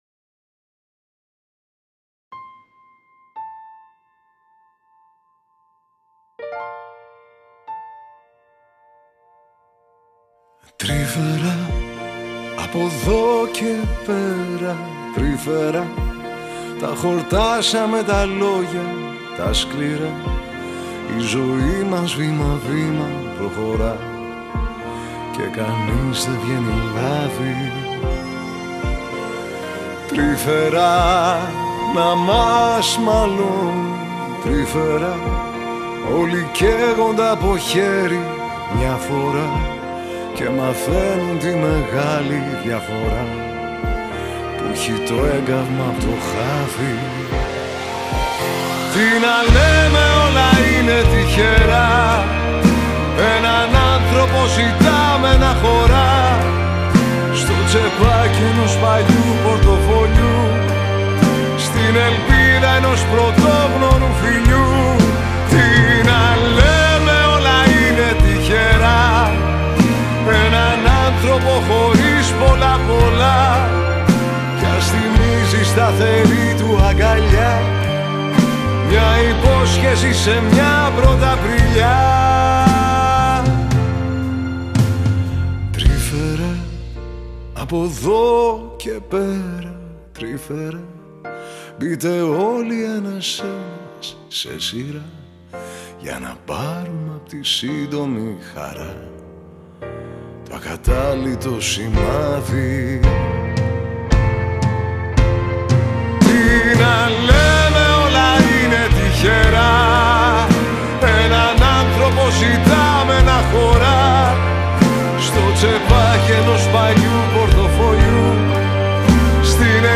Μπάσο
Τύμπανα
Τσέλο
Βιολί